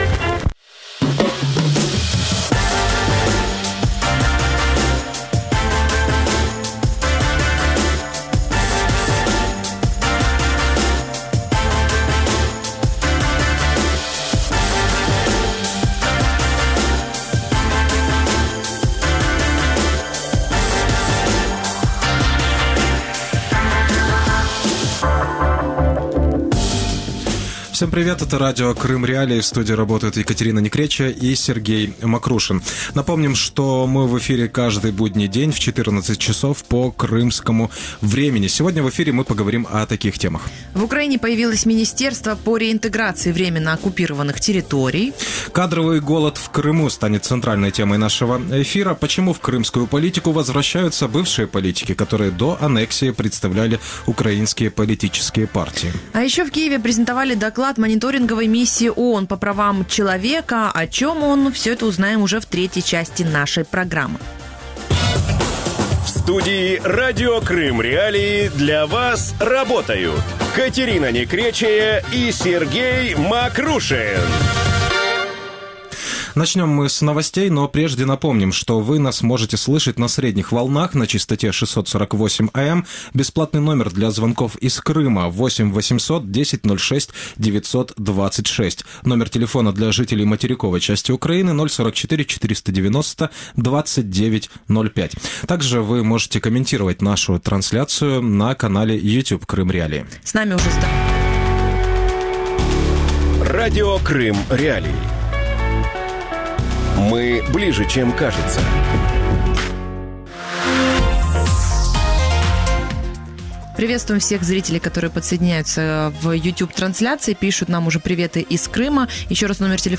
Кадровый голод в Крыму | Дневное ток-шоу